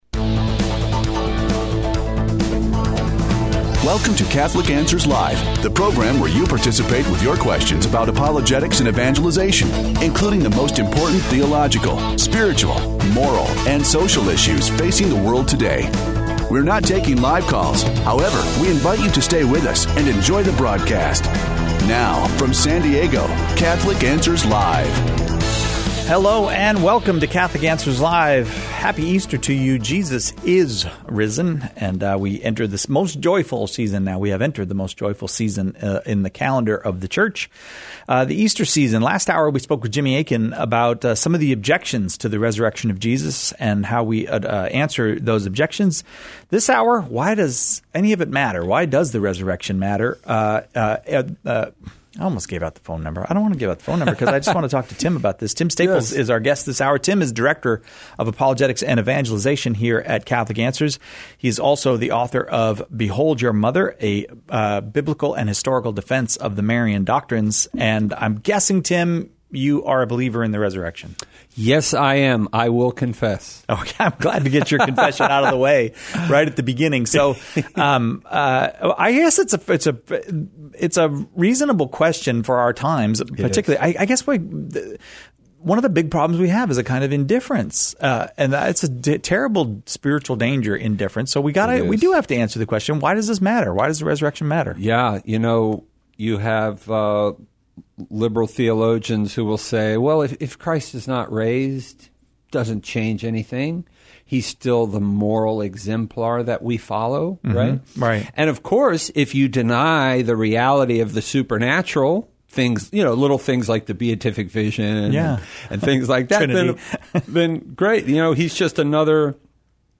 Why The Resurrection Matters (Pre-recorded)